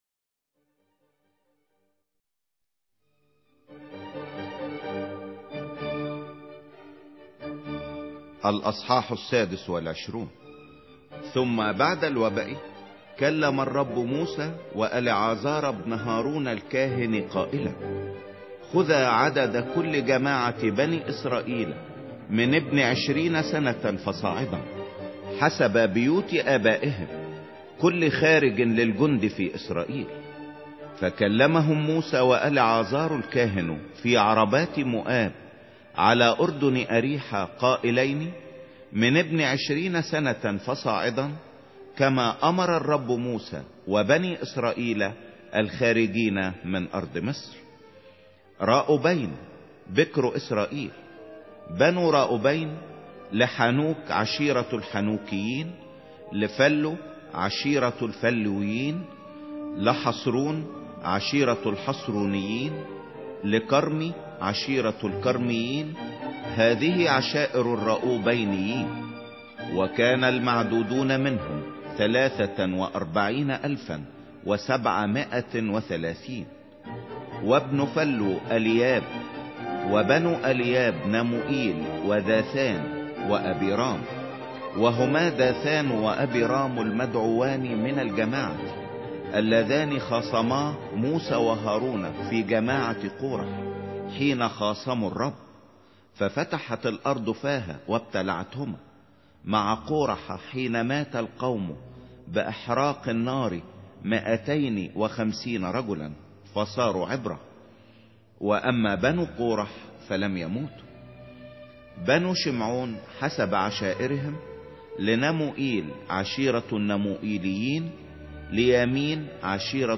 سفر العدد 26 مسموع